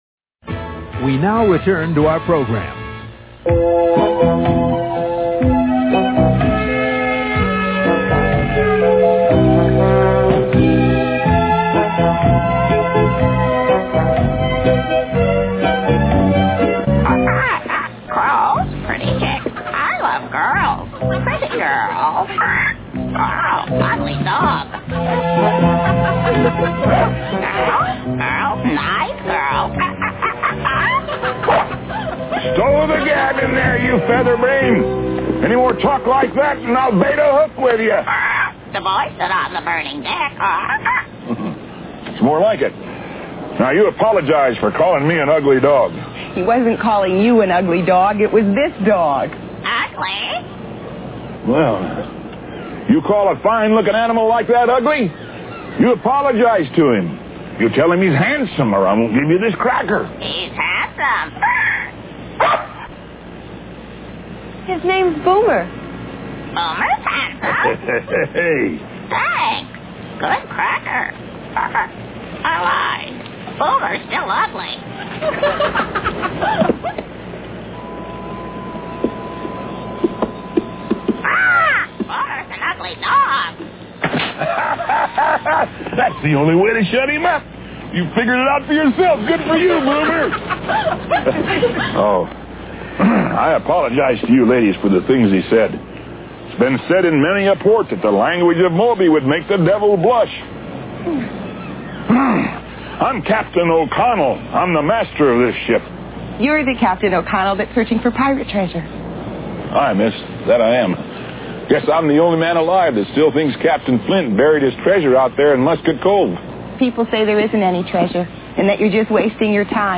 The last treat is a short clip from the Musket Cove Treasure episode, aired Jan. 10, 1982, which comes from my tape.
Ogg files should sound better, but maybe not on this page of low-fi TV tapes..